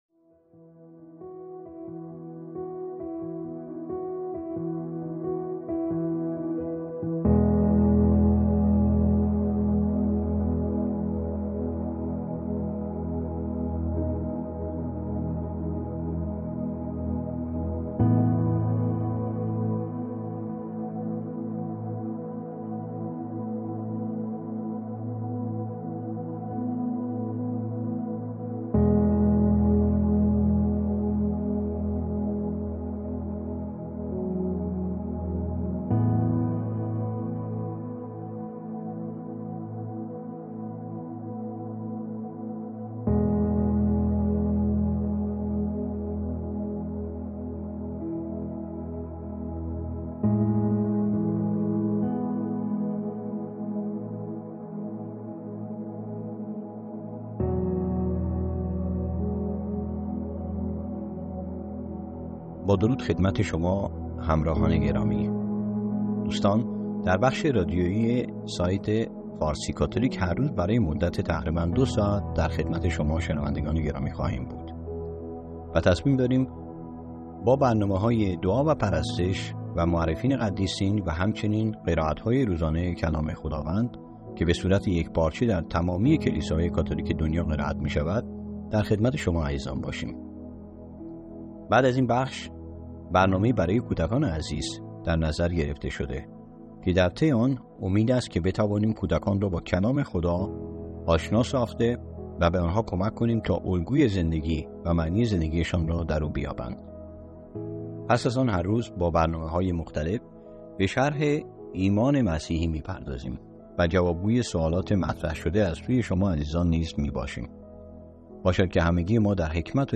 حدود دو ساعت برنامه های صوتی روزانه فارسی کاتولیک به شکل فایل، شامل انواع دعا،قرائت های کلیسایی و... برای شما عزیزان آماده گشته است